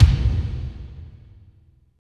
35.10 KICK.wav